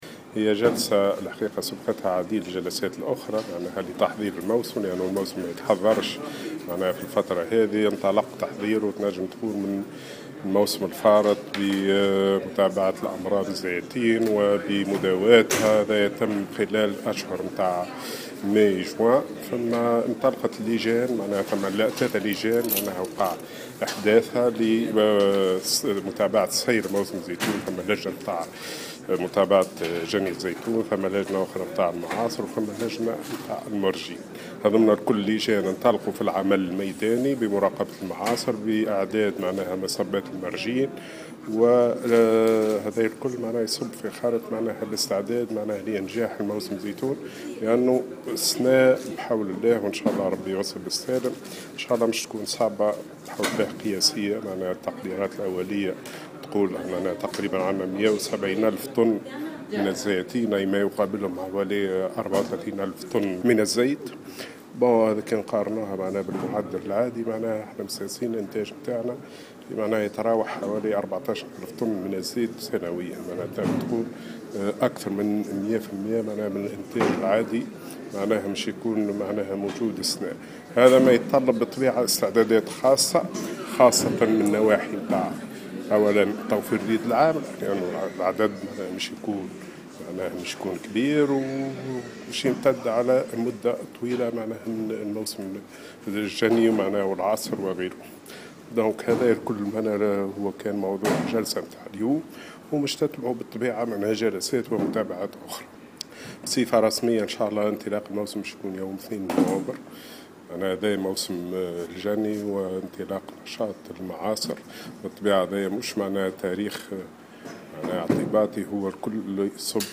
في تصريح لمبعوثة الجوهرة اف ام خلال جلسة عمل انتظمت اليوم في سوسة حول موسم الزيتون